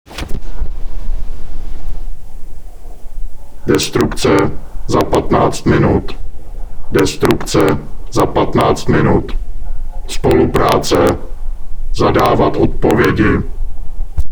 mluvené pokyny 3 ks soubory: